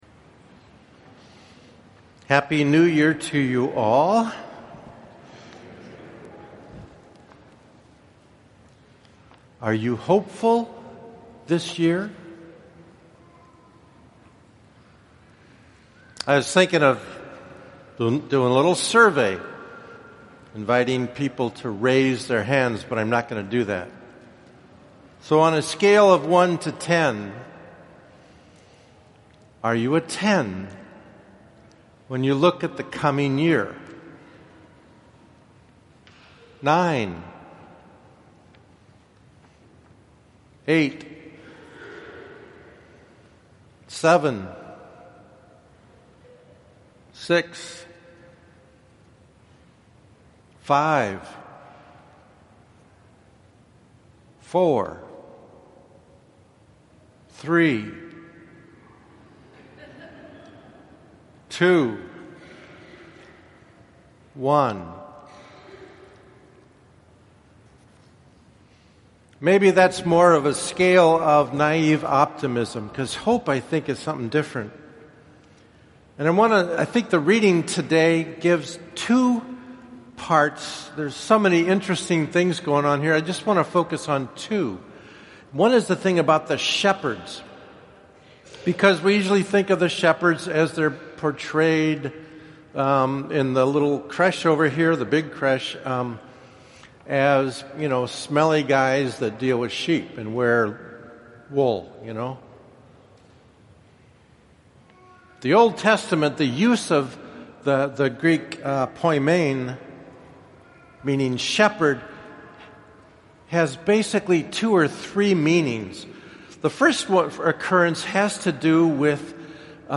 Homily – Solemnity of Mary 2017 (fin)